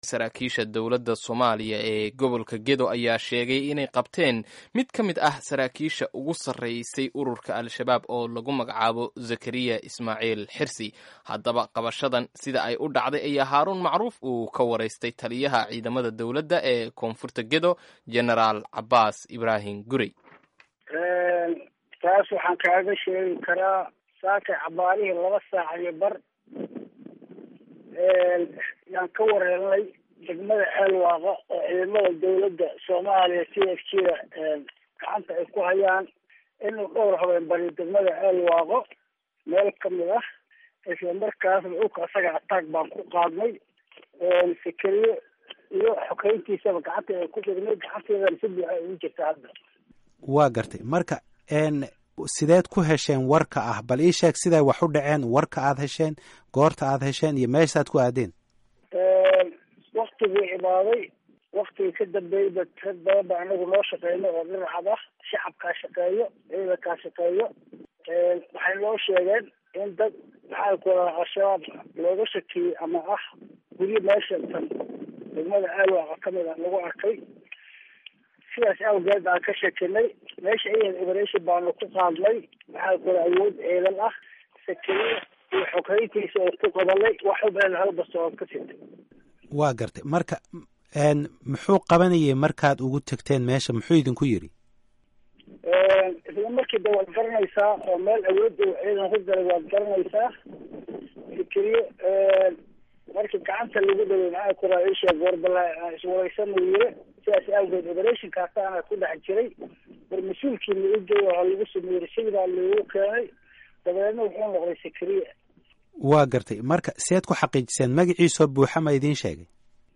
Dhegayso: Waraysi ku Saabsan Sarkaal Sare oo Shabaab laga Qabtay